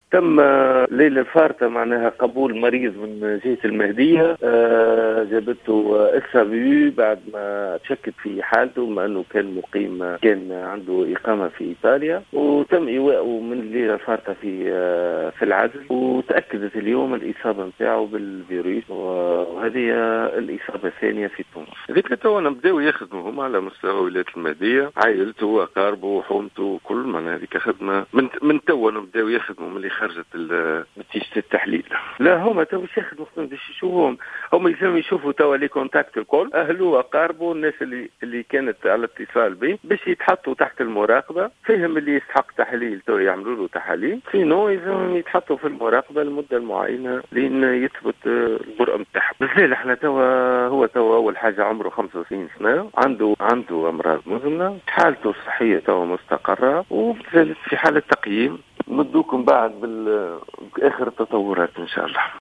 المدير الجهوي للصحة بسوسة يقدم معطيات بخصوص المصاب الثاني بالكورونا